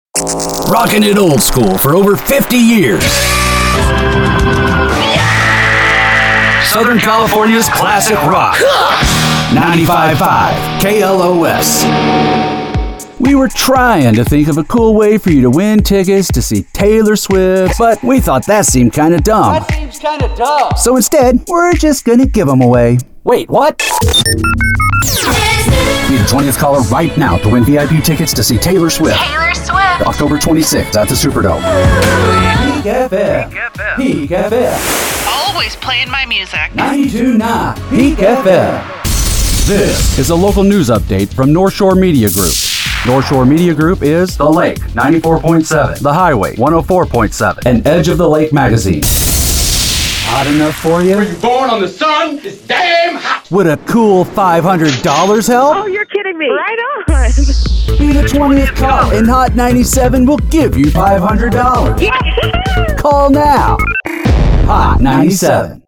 Radio Imaging Demo